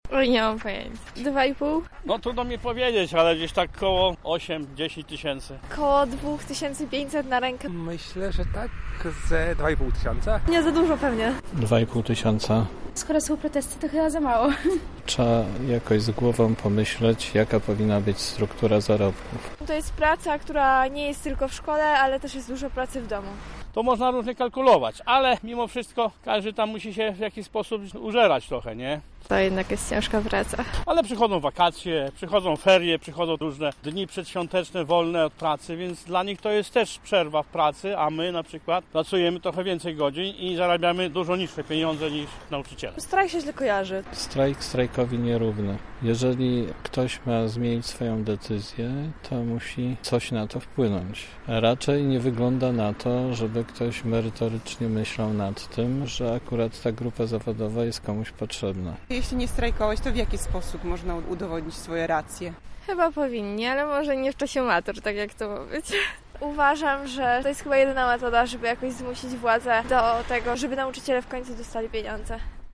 Sonda wśród mieszkańców Lublina